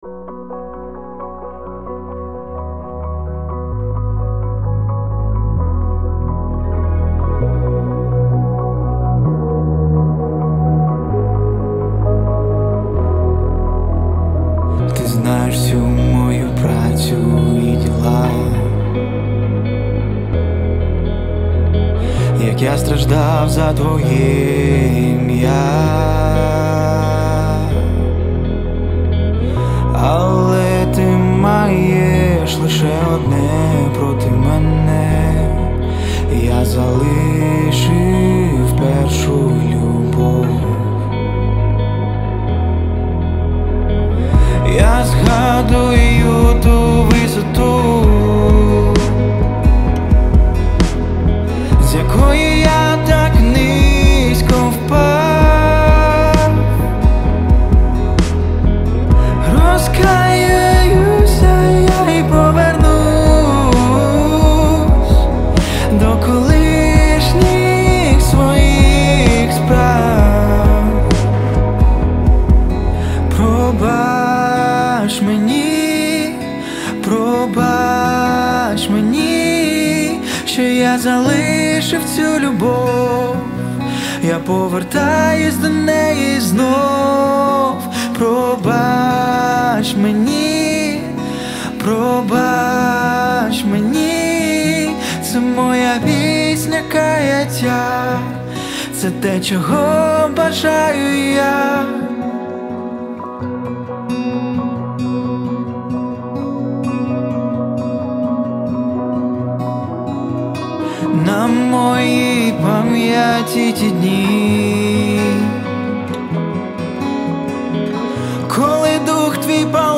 140 просмотров 116 прослушиваний 22 скачивания BPM: 130